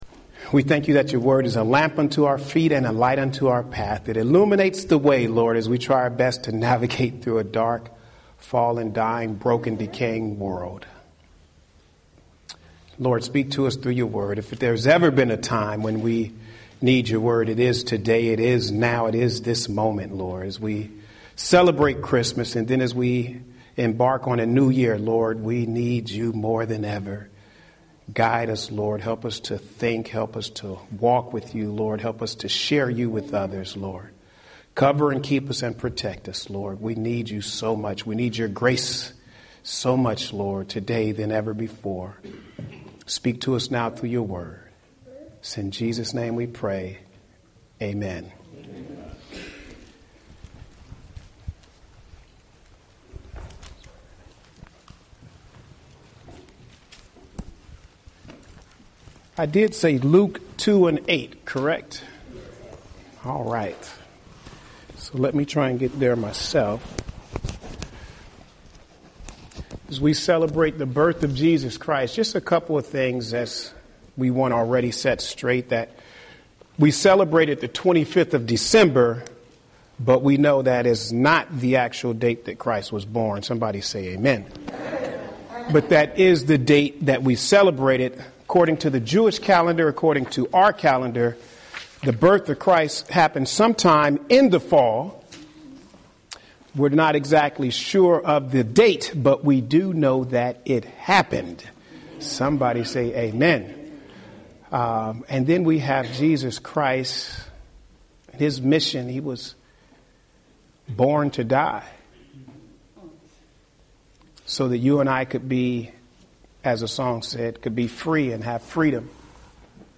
Home › Sermons › Merry Christmas – The Evidence Is Clear, A Savior Is Born!